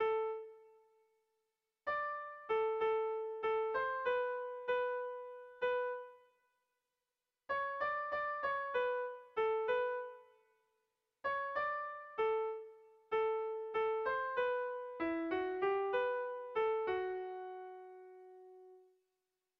Dantzakoa
Araba < Euskal Herria
A1A2